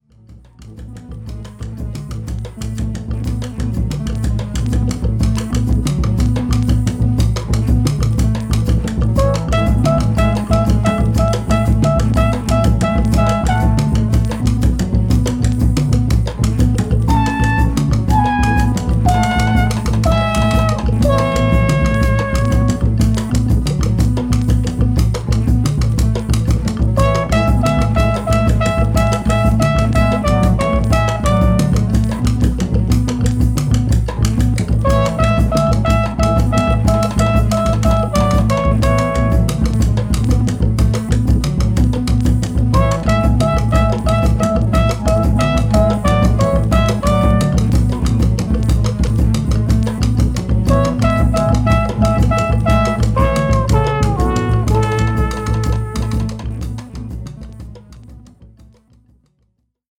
コルネット奏者